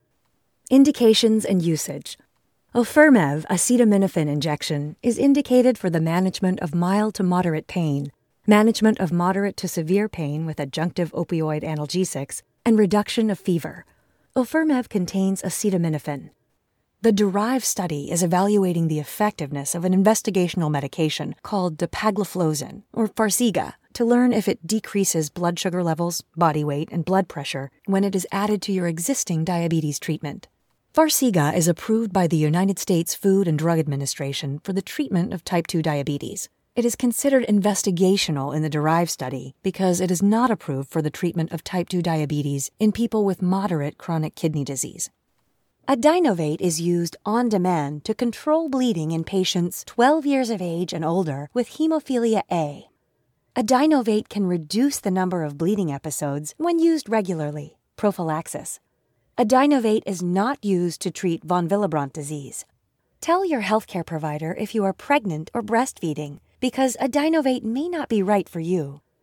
Seasoned voiceover actor with a broad range of skills
Medical Demo